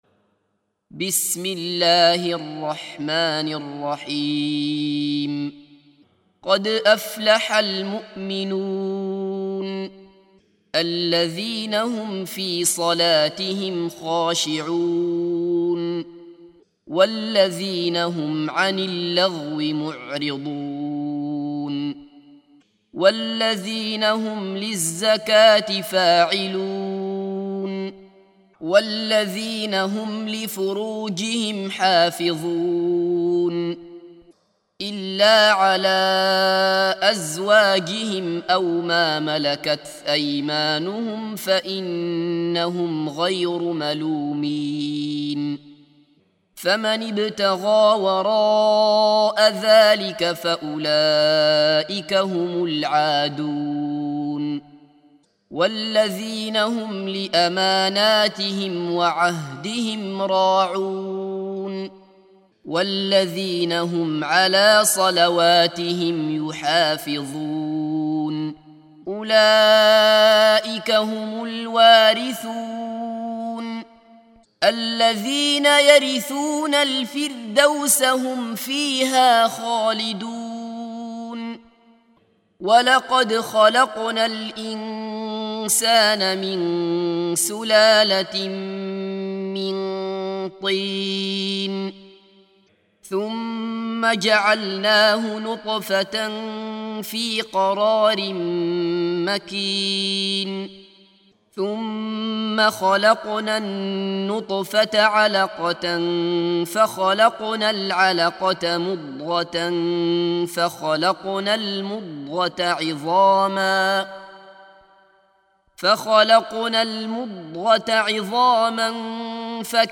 سُورَةُ المُؤۡمِنُونَ بصوت الشيخ عبدالله بصفر